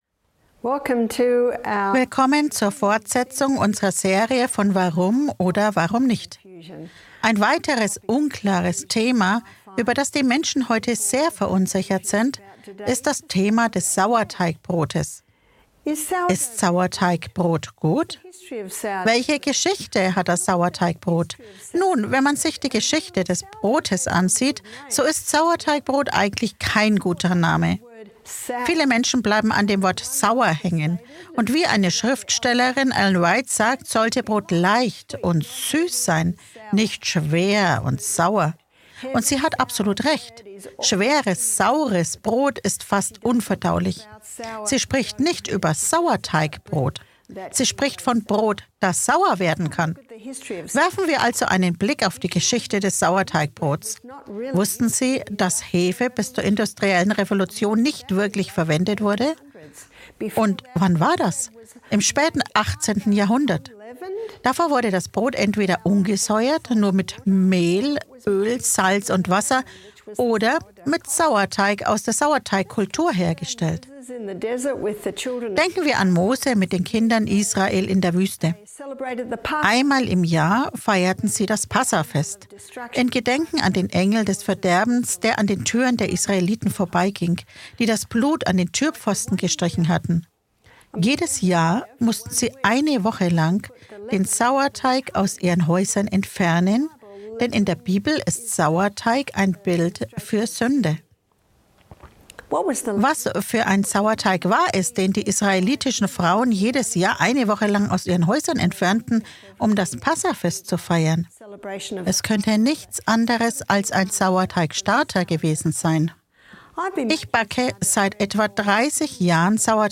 In diesem spannenden Vortrag wird die Geschichte und Gesundheit des Sauerteigbrotes beleuchtet. Von alten Traditionen bis zu modernen Backtechniken zeigt sich, dass Sauerteigbrot nicht nur ein Genuss ist, sondern auch leichter verdaulich sein kann.